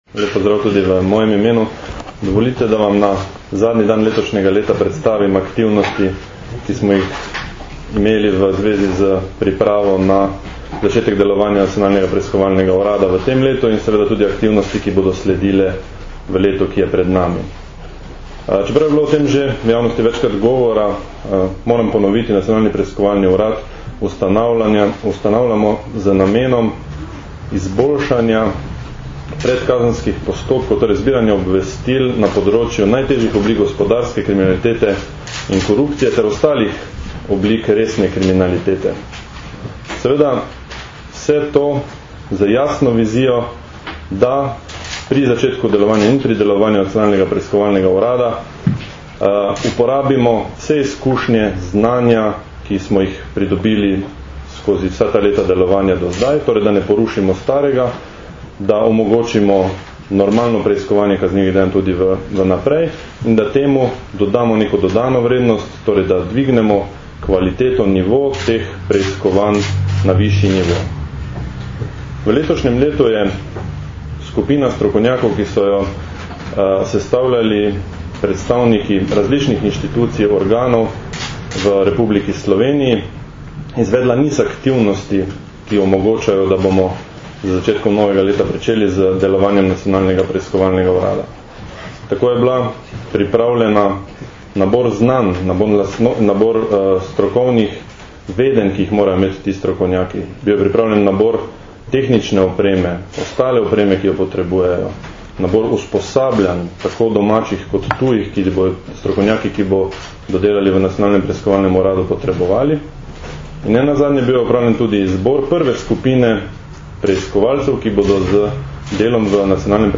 V izjavi za javnost je danes, 31. decembra 2009, predstavil dosedanje in načrtovane aktivnosti Nacionalnega preiskovalnega urada (NPU).
Zvočni posnetek izjave Roberta Črepinka  (mp3)